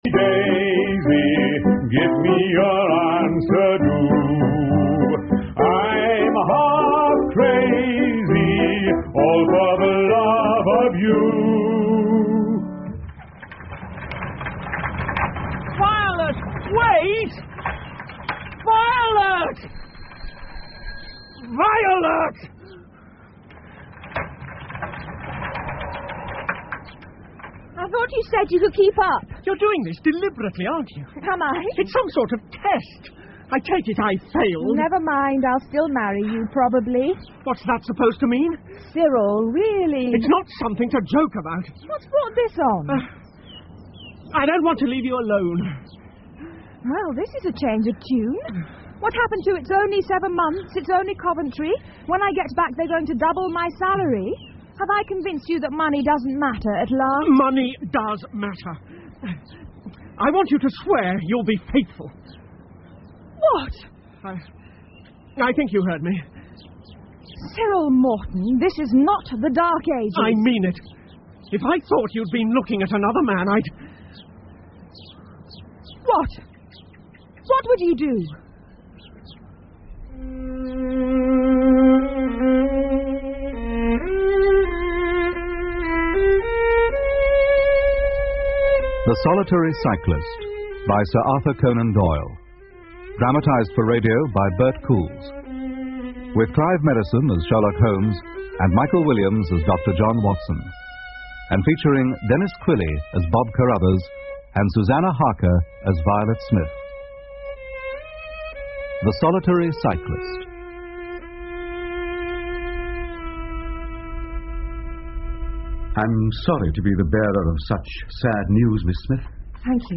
在线英语听力室福尔摩斯广播剧 The Solitary Cyclist 1的听力文件下载,英语有声读物,英文广播剧-在线英语听力室